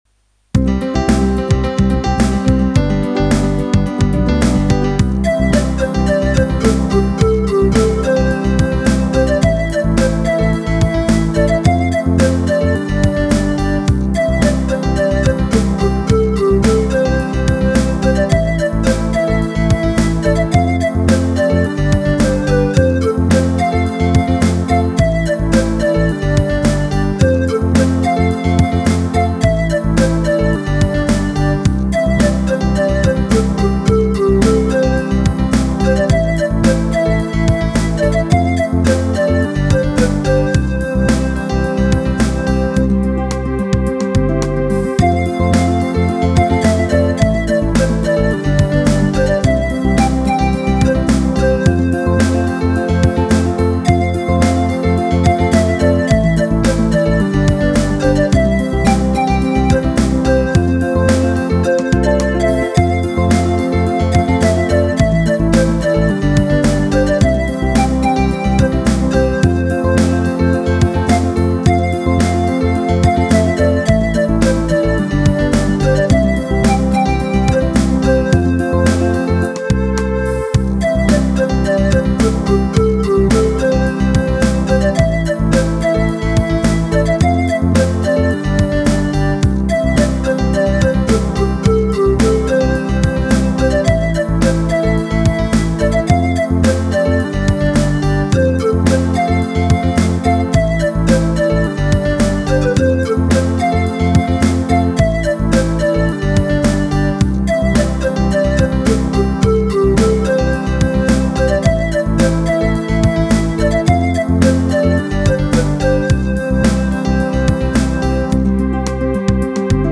Ik voeg steeds nieuwe ritmedemo's bovenaan toe, scroll dus naar beneden om alles te zien.
027 16 Beat Pop